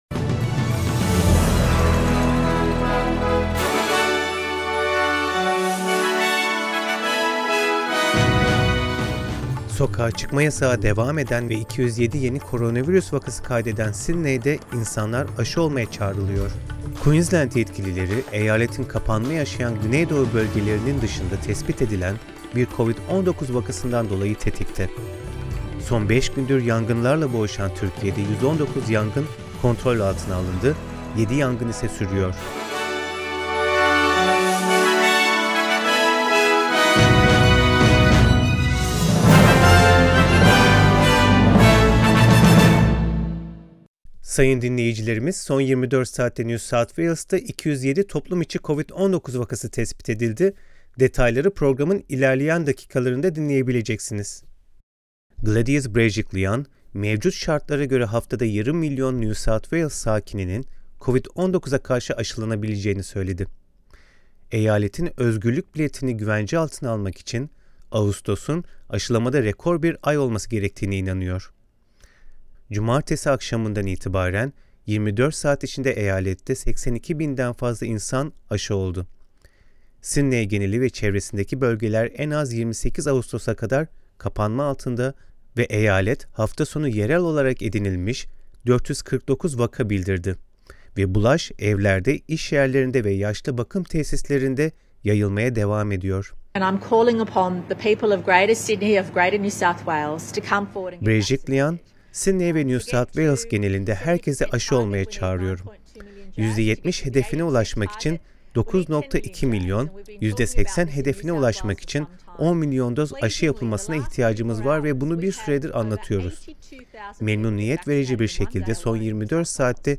SBS Türkçe Haberler 2 Ağustos